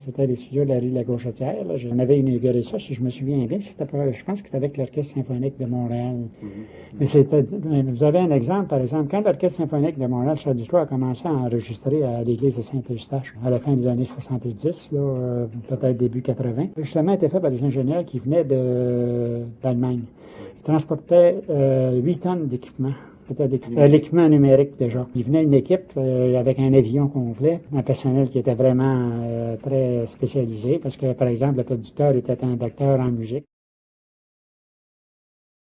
Extraits sonores des invités (Histoire des maisons de disques)